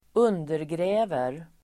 Ladda ner uttalet
Uttal: [²'un:dergrä:ver]